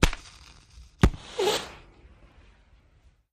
Smoke Grenade: Pop And Ignite Close-up. Stereo